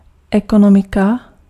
Ääntäminen
IPA: /e.ko.no.mi:/